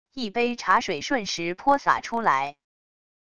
一杯茶水瞬时泼洒出来wav音频